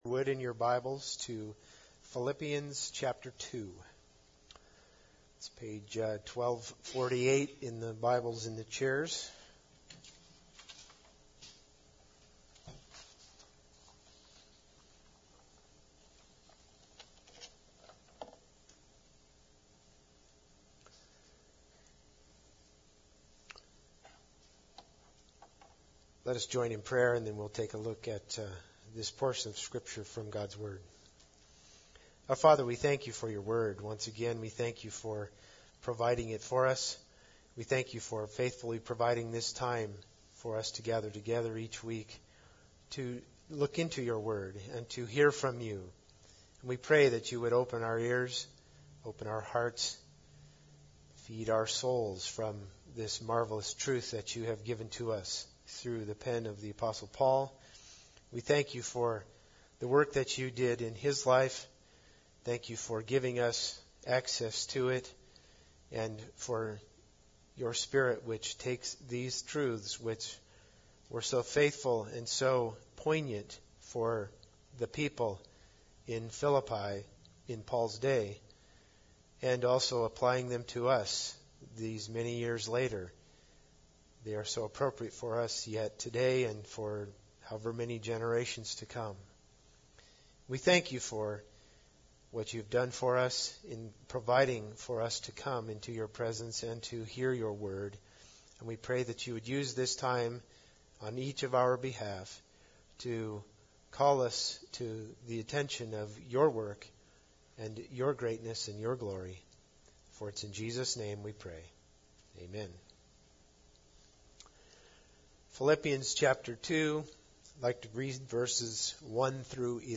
Philippians 2:1-11 Service Type: Sunday Service Bible Text